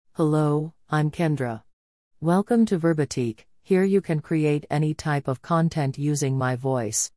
KendraFemale US English AI voice
Kendra is a female AI voice for US English.
Voice sample
Listen to Kendra's female US English voice.
Female